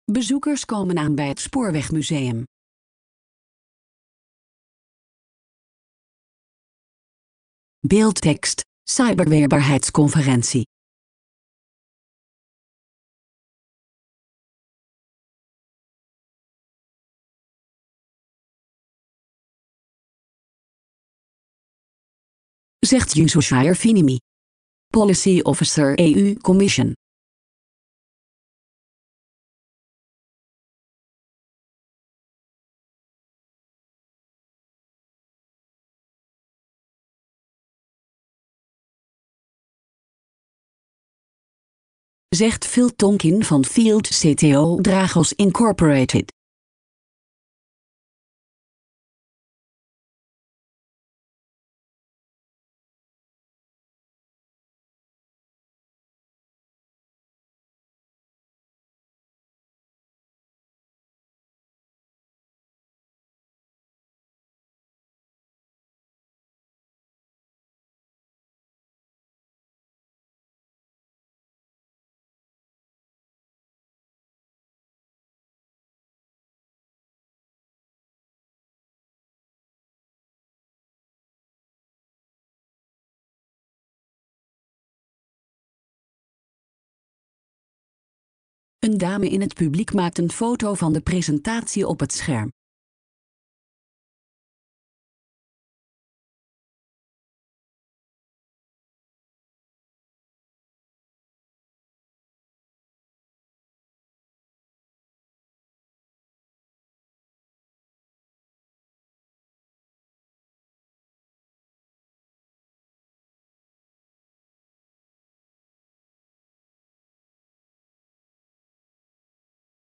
Zie hieronder een sfeerimpressie van de conferentie.